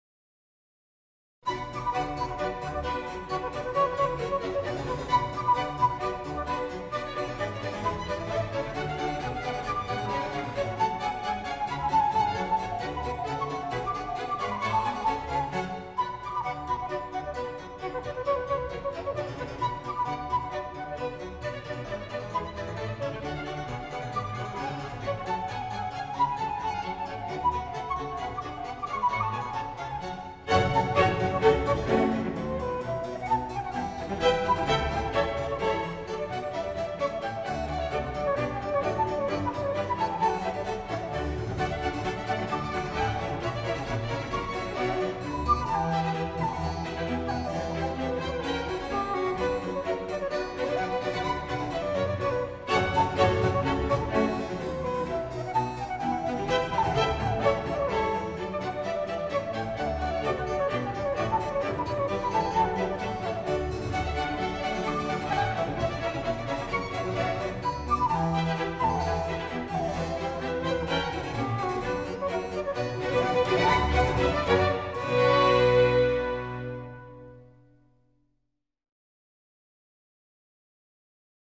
19-Suite-No.-2-for-Flute-Strings-and-Basso-Continuo-Bach.m4a